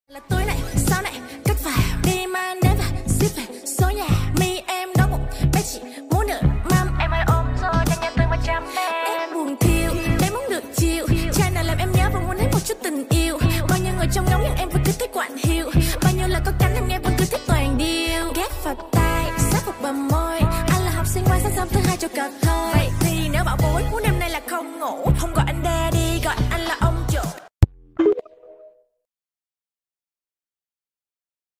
bruhhh sound effects free download